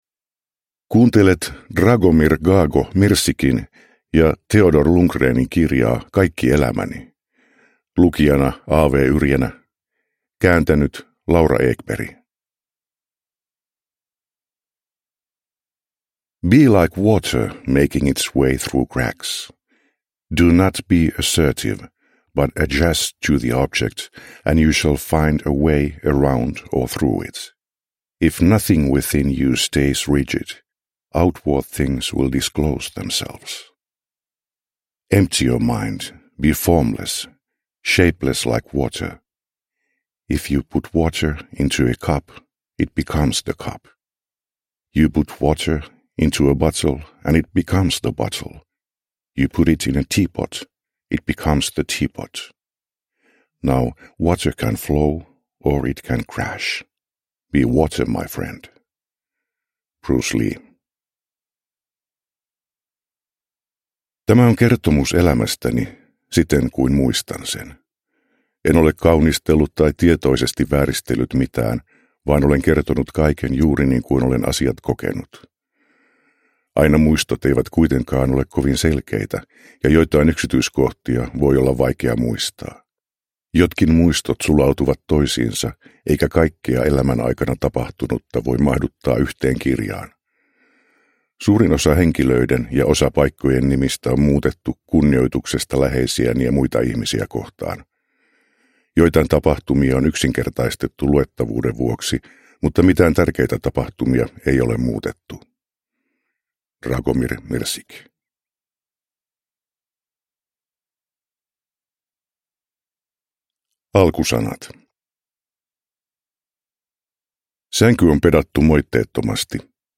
Kaikki elämäni – Ljudbok – Laddas ner
Uppläsare: A. W. Yrjänä